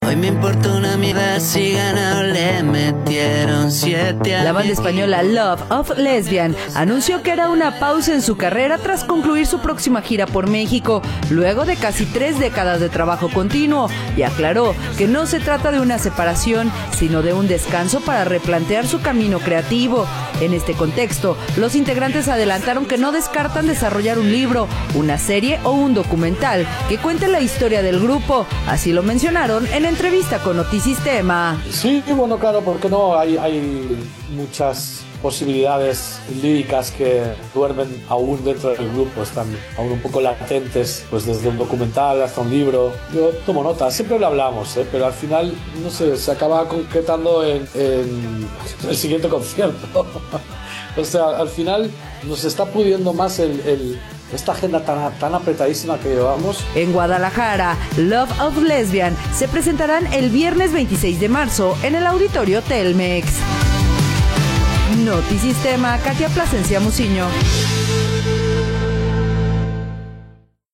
audio La banda española Love of Lesbian anunció que hará una pausa en su carrera tras concluir su próxima gira por México, luego de casi tres décadas de trabajo continuo y aclaró que no se trata de una separación, sino de un descanso para replantear su camino creativo. En este contexto, los integrantes adelantaron que no descartan desarrollar un libro, una serie o un documental que cuente la historia del grupo, así lo mencionaron en entrevista con Notisistema.